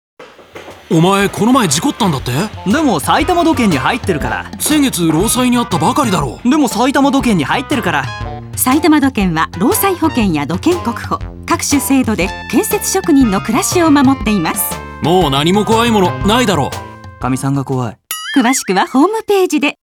埼玉土建ラジオCMアーカイブ